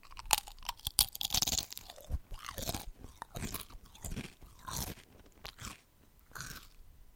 嘴里发出的声音 " Blop around 70 Bb3 Clair mf 1
描述：使用AKG C214麦克风录制口腔噪音
标签： 噪声
声道立体声